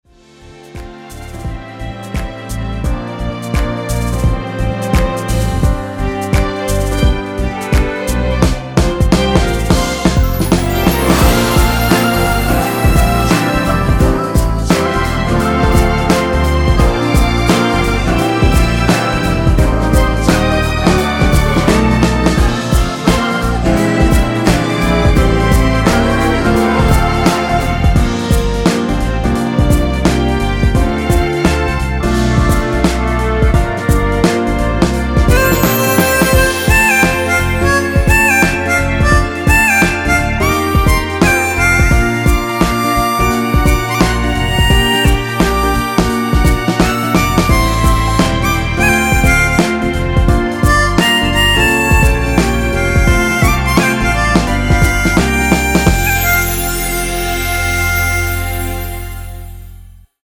엔딩이 페이드 아웃이라서 노래하기 편하게 엔딩을 만들어 놓았으니 미리듣기 확인하여주세요!
원키에서(-3)내린 코러스 포함된 MR입니다.
앨범 | O.S.T
앞부분30초, 뒷부분30초씩 편집해서 올려 드리고 있습니다.